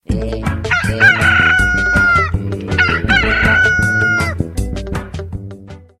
알림음(효과음) + 벨소리
알림음 8_새벽을알리는꼬끼오.ogg